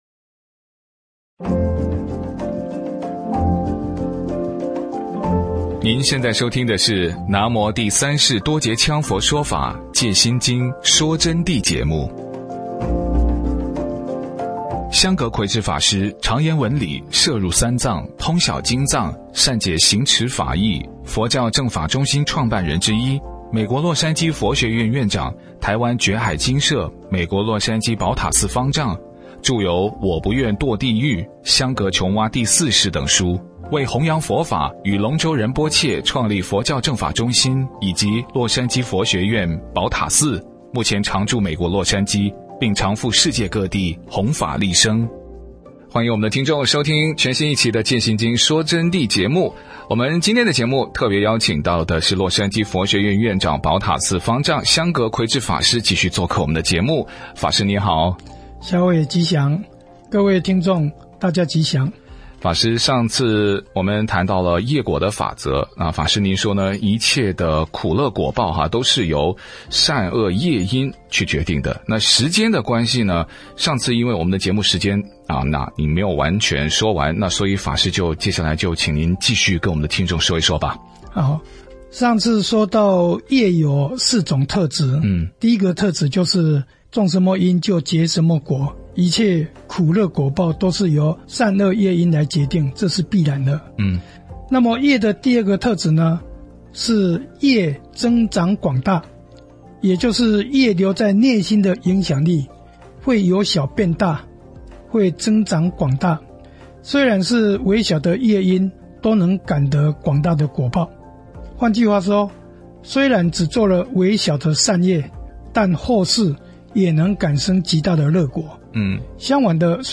佛弟子访谈（二十七）业果的特质与如何消除宿业转换因果？